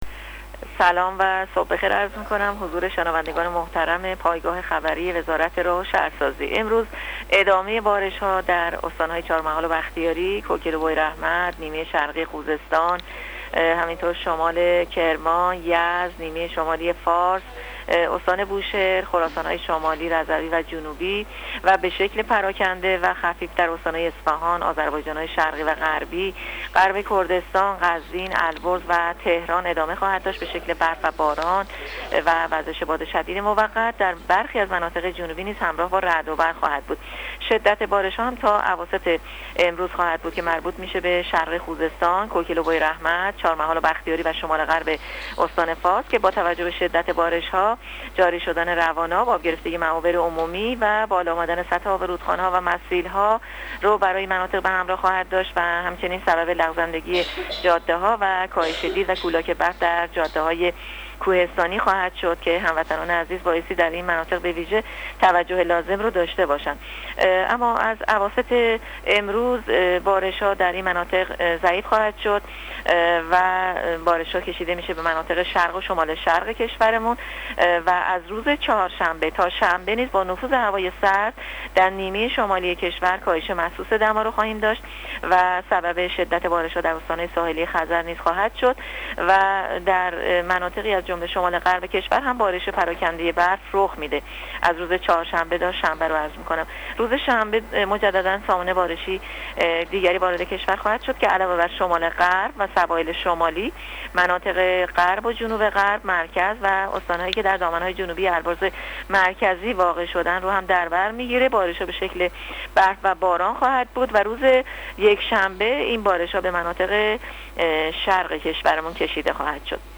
کارشناس سازمان هواشناسی کشور در گفت‌وگو با رادیو اینترنتی وزارت راه‌وشهرسازی، آخرین وضعیت آب‌و‌هوای کشور را تشریح کرد.
گزارش رادیو اینترنتی از آخرین وضعیت آب‌‌و‌‌‌هوای سی‌ام آذر؛